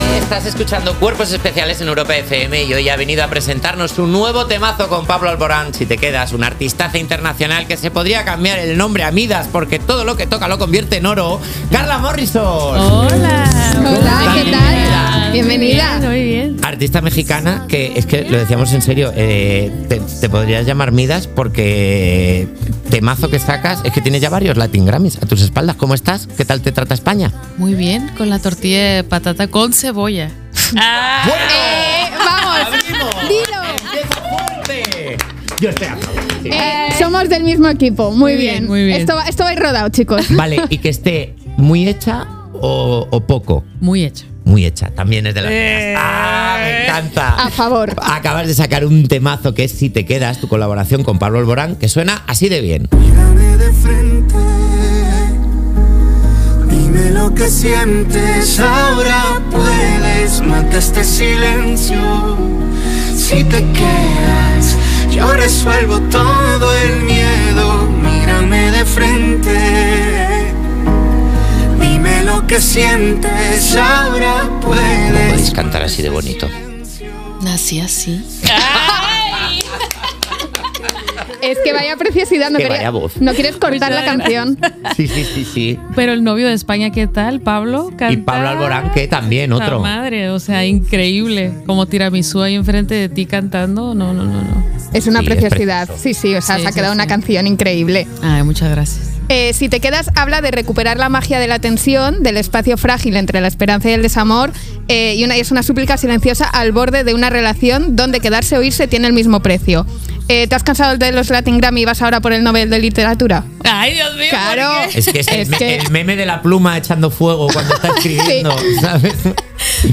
La entrevista de Carla Morrison en 'Cuerpos especiales'